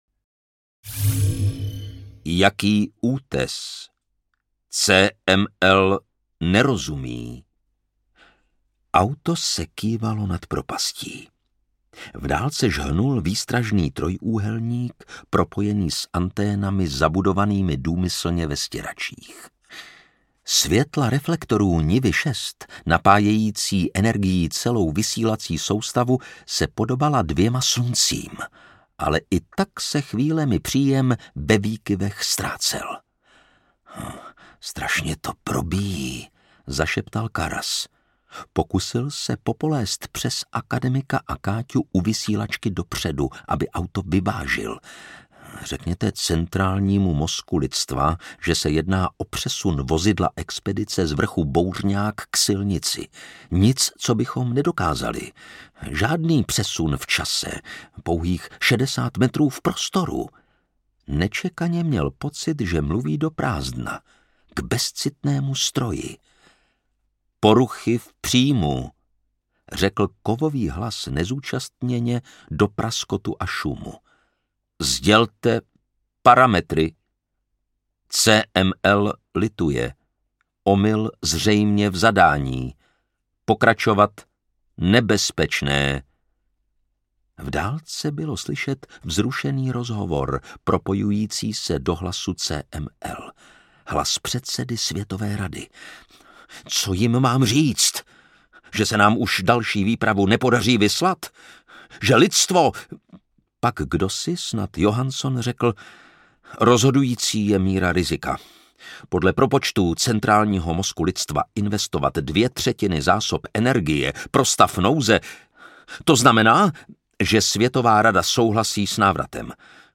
Ukázka z knihy
Čte Lukáš Hlavica.
Vyrobilo studio Soundguru.